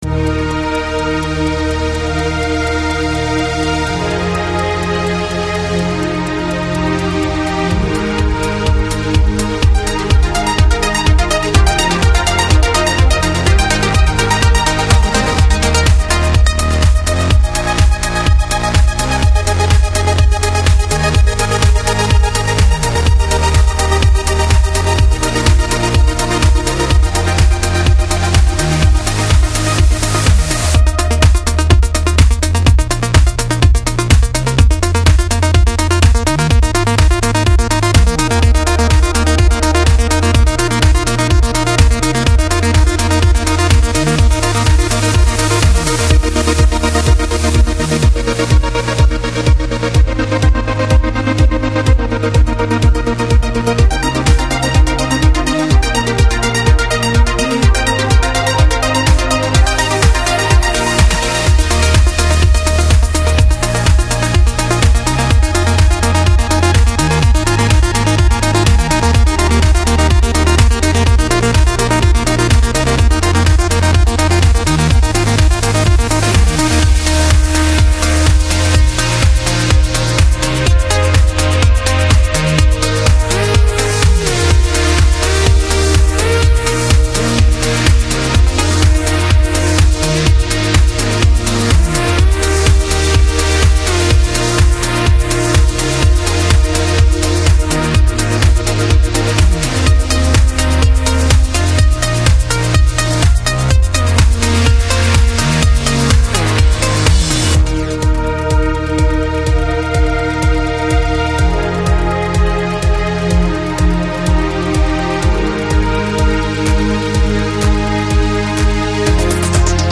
Жанр: Downtempo, Electronic